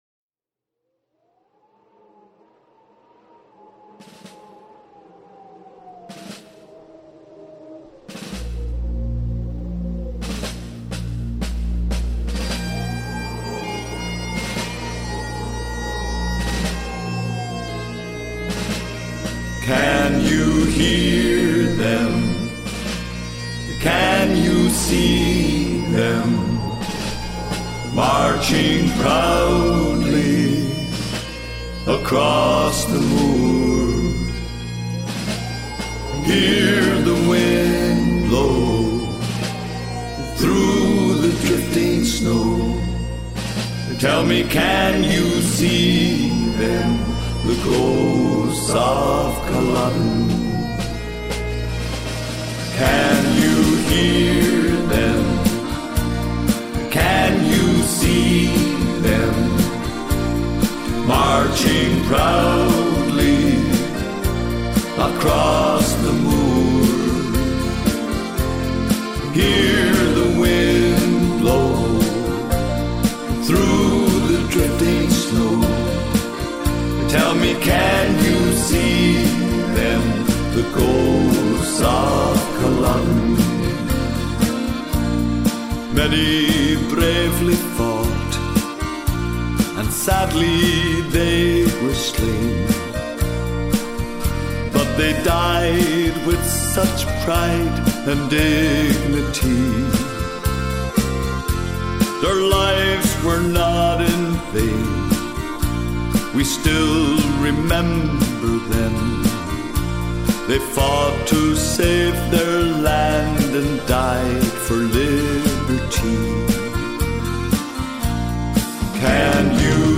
Genre: Disco.